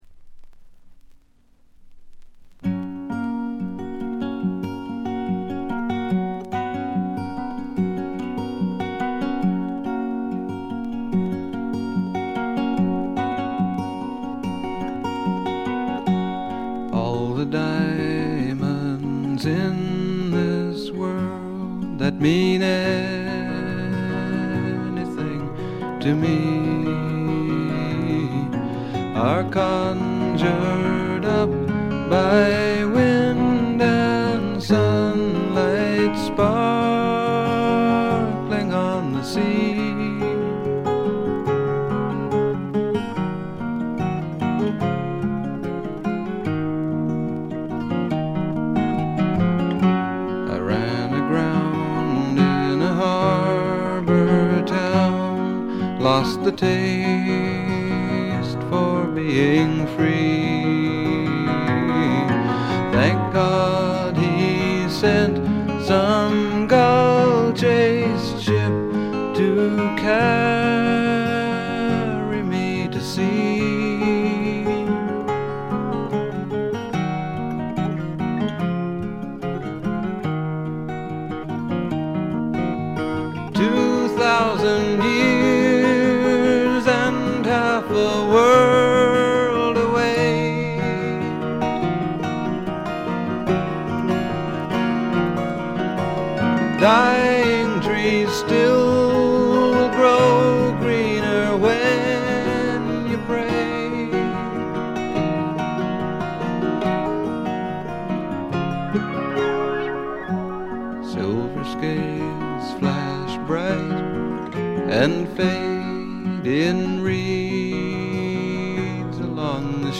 ところどころでチリプチ。
静謐で内省的なホンモノの歌が聴ける名作です。
ほとんどギターの弾き語りで、ギターインストのアコギの腕前も素晴らしいです。
試聴曲は現品からの取り込み音源です。